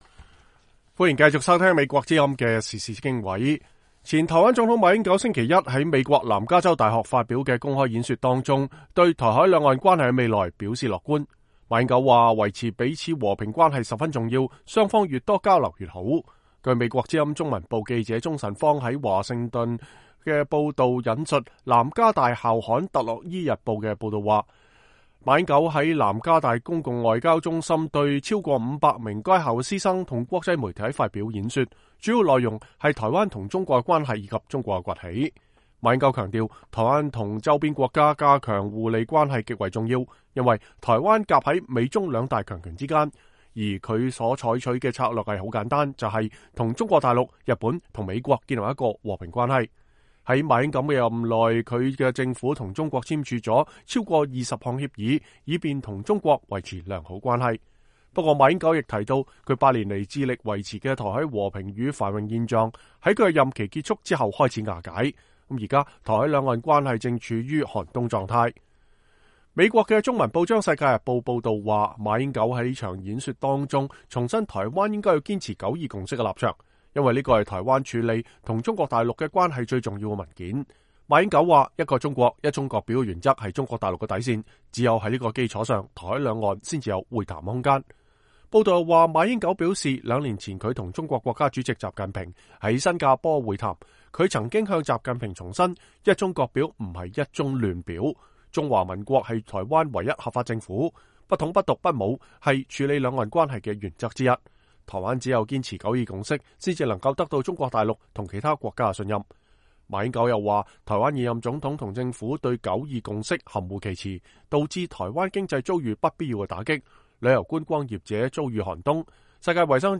前台灣總統馬英九訪問美國西部在大學演講